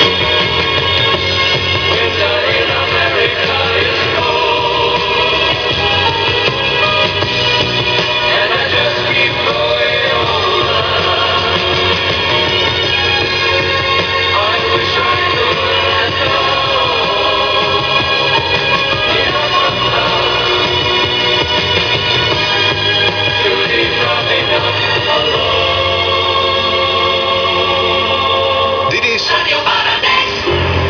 Largest Matchbox radio
Both were reproduced in my garden, Jinx is even so strong that some distortion is heard, but volume can be controlled by rotating the radio.